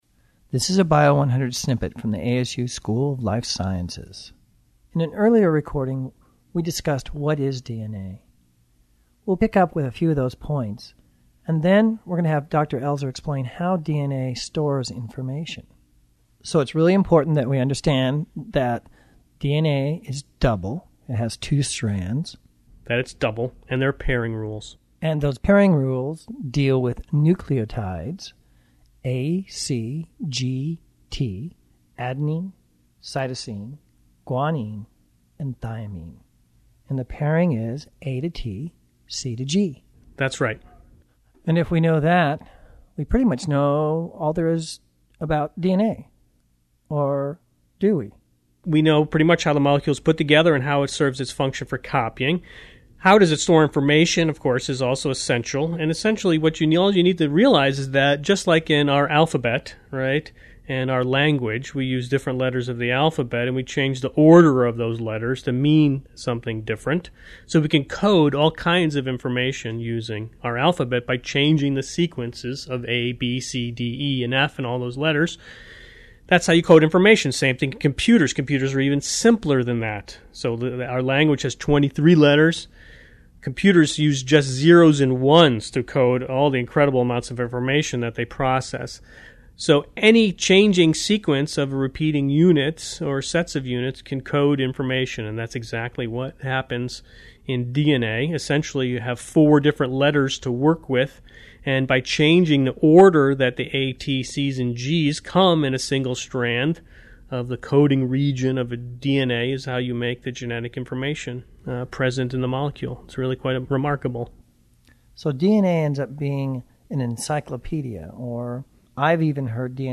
What if you could get a private lecture on DNA, genes, and genomes?